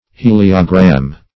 (h[=e]"l[i^]*[-o]*gr[.a]m)
heliogram.mp3